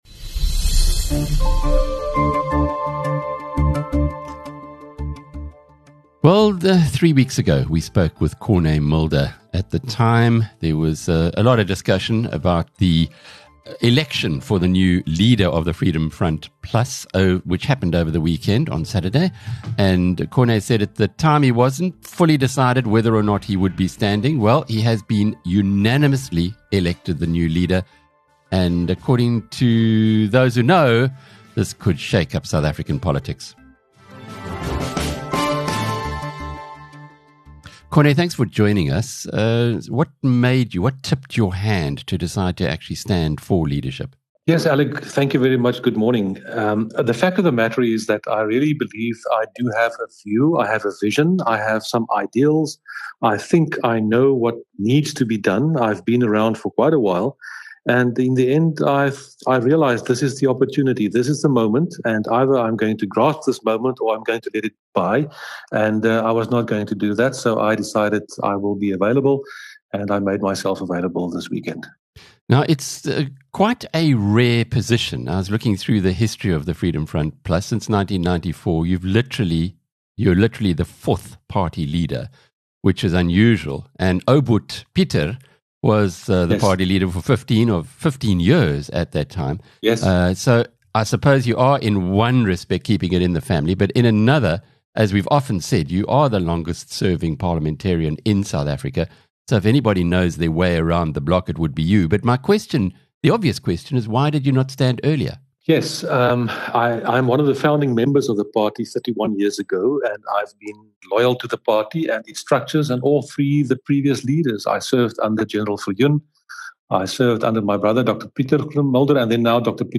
Saturday’s closely watched FF+ leadership election ended up as a no-contest with SA’s longest serving Parliamentarian Dr Corné Mulder appointed its new leader. In this wide-ranging interview Mulder explains how the party which champions minority rights will play its hand in future.